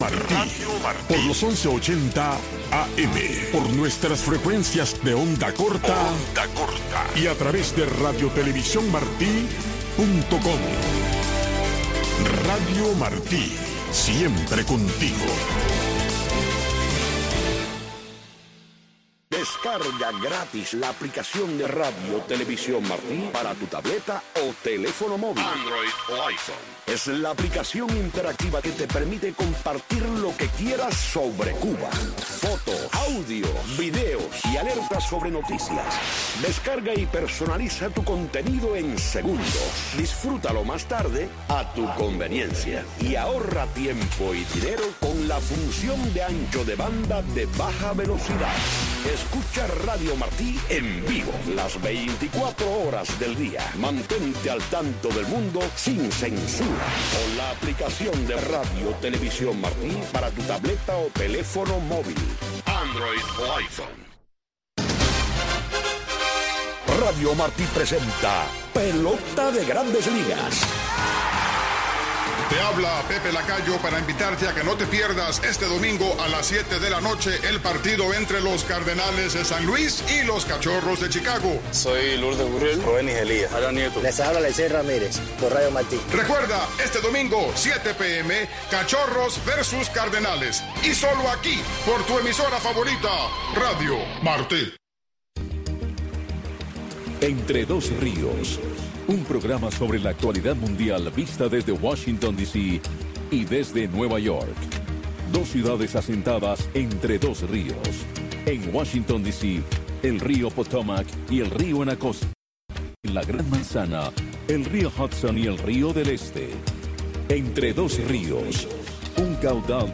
Radio Martí les ofrece una revista de entrevistas, información de la actualidad mundial vista desde el punto de vista, Entre Dos Rios.